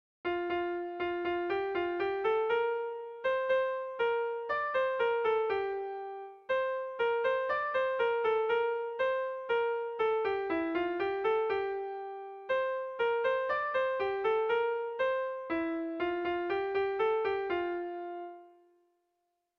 Erromantzea
ABD